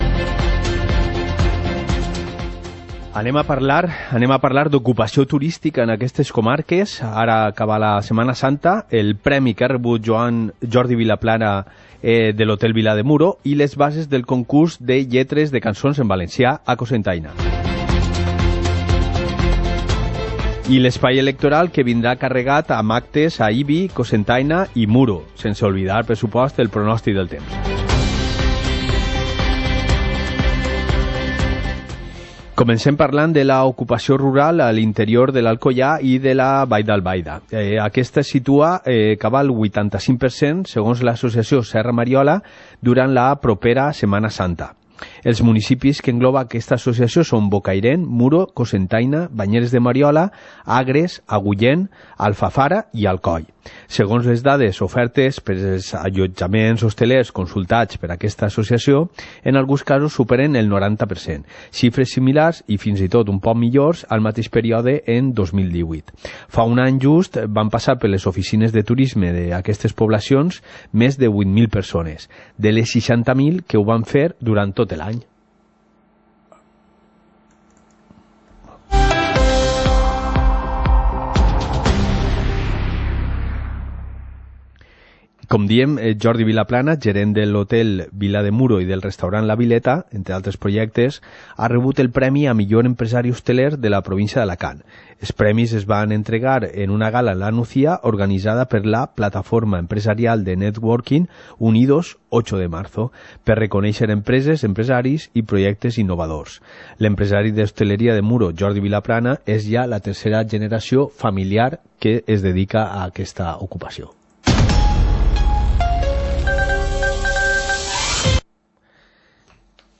Informativo comarcal - martes, 16 de abril de 2019